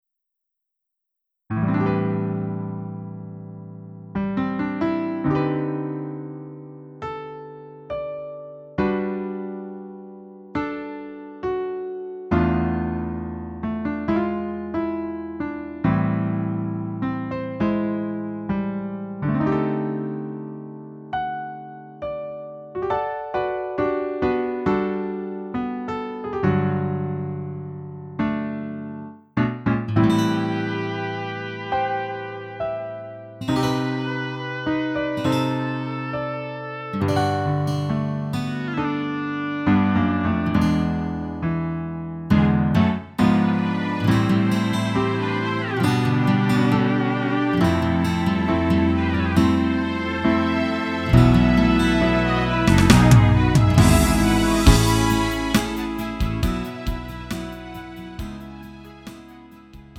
음정 원키 4:13
장르 가요 구분 Lite MR